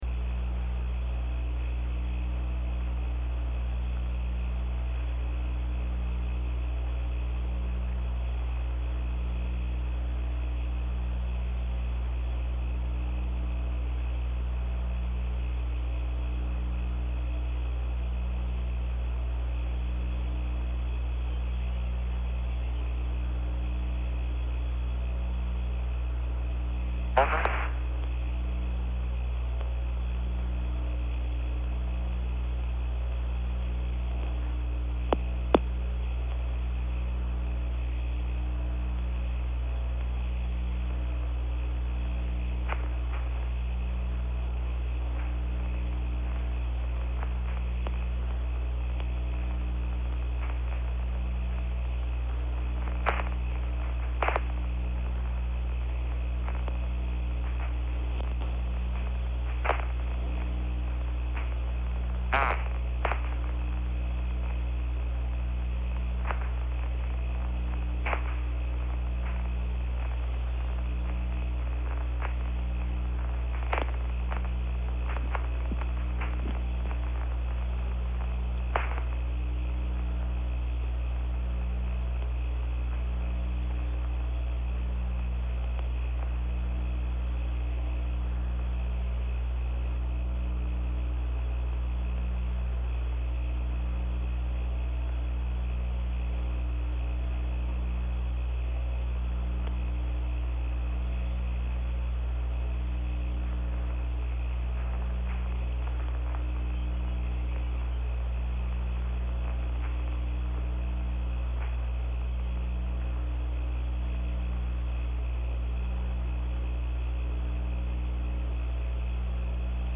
Corrected Transcript and Commentary